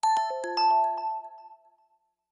mention_received.ogg